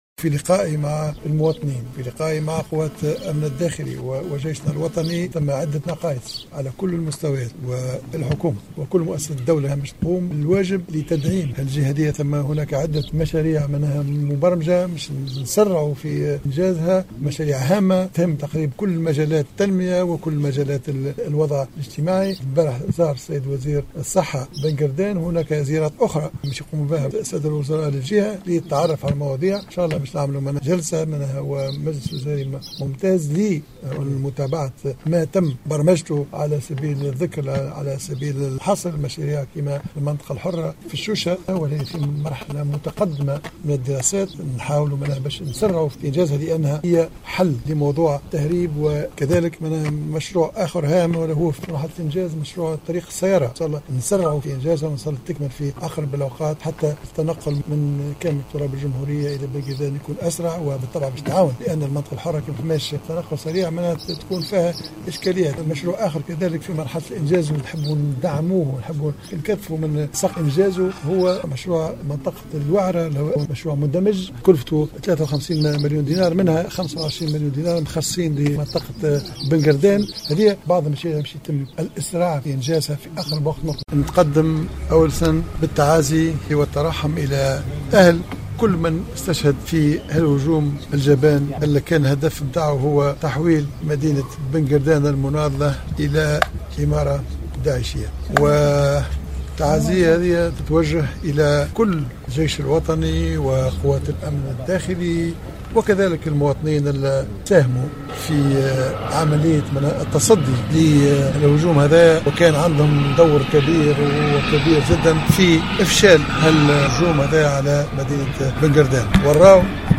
أعلن رئيس الحكومة الحبيب الصيد خلال لقاء إعلامي بنادي الضباط بالثكنة العسكرية بجلال بمناسبة زيارته صباح اليوم إلى مدينة بن قردان عن جملة من الاجراءات ستكون محل متابعة قريبا في مجلس وزاري ممتاز سيعقد بالمناسبة وعن برمجة زيارات قريبة لعدد من الوزراء وذلك في إطار تكثيف العناية بهذه المعتمدية.